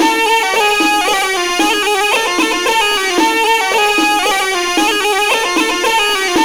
DRUM+PIPE2-R.wav